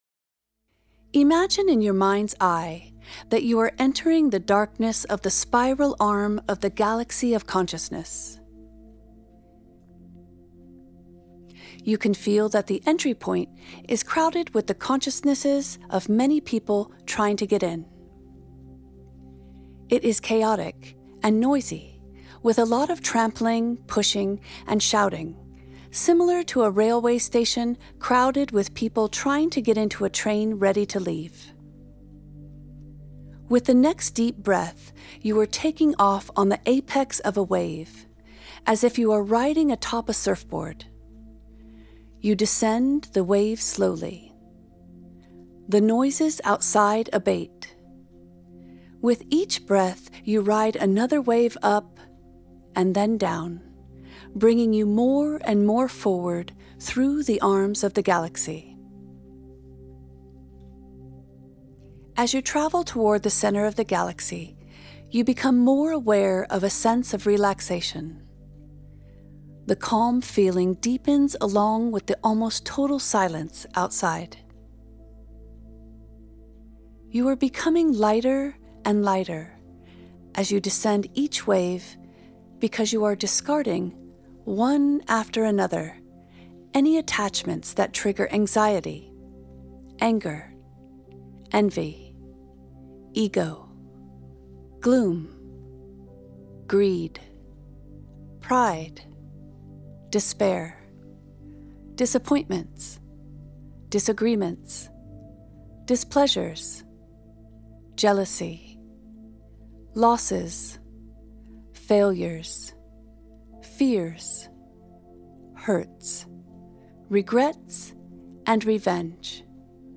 Meditation Exercise